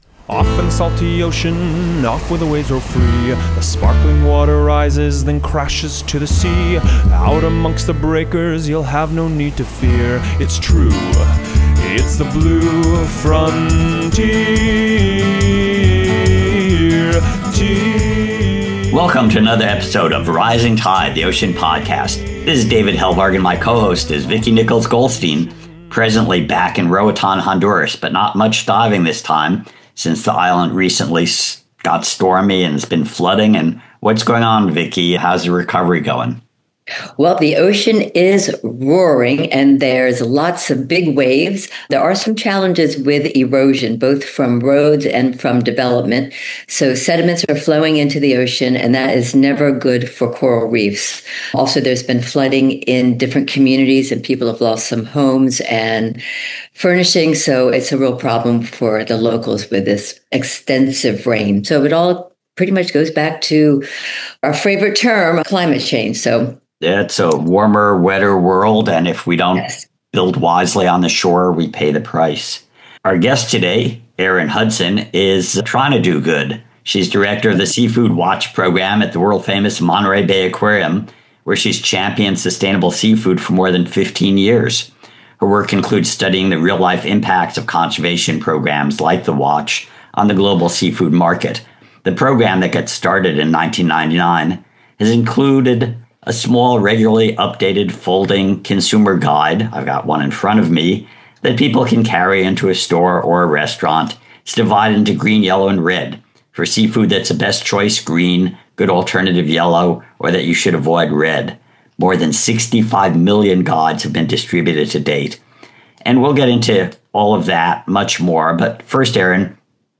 The conversation traces the origins of Seafood Watch’s iconic red, yellow, and green pocket guides—a simple, powerful tool that helps people understand which seafood choices are environmentally responsible, risky, or best avoided.